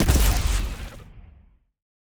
weapon_flame_008.wav